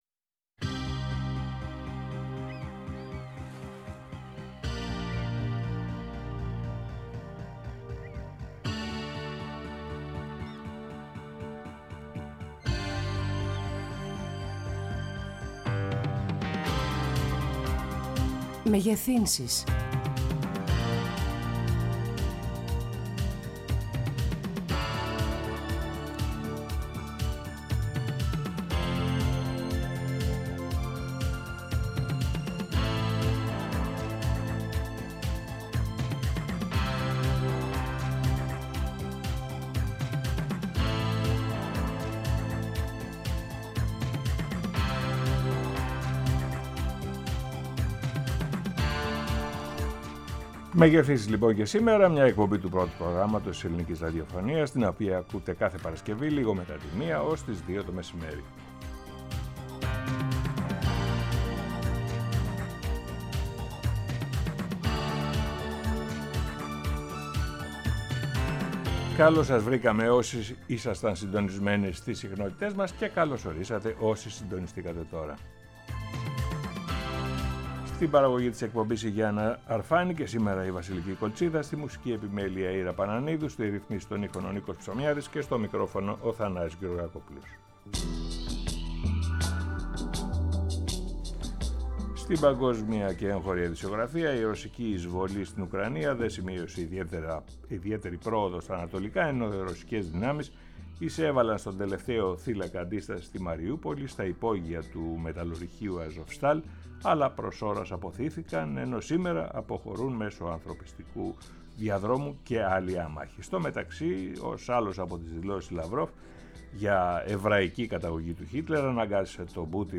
Μια εκπομπή η οποία κάνει μεγεθύνσεις στις θεατές και αθέατες όψεις της επικαιρότητας, της βδομάδας που κλείνει και προσπαθεί με συνομιλίες με ανθρώπους που -κατά τεκμήριο- γνωρίζουν και με σχολιασμό να βουτά στο …. βυθό αυτής της επικαιρότητας για να βρει τα ….. μαργαριτάρια του.